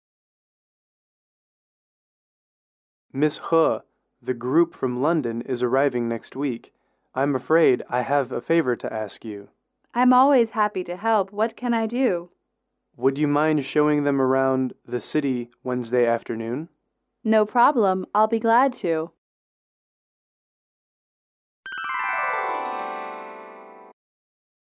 英语主题情景短对话29-1：请人帮忙带团(MP3)